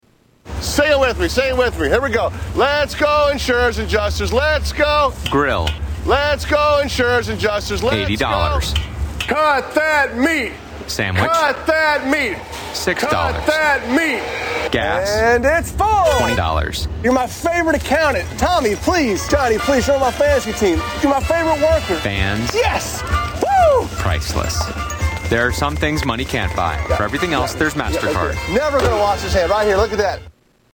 Tags: Media MasterCard Advertisement Commercial MasterCard Clips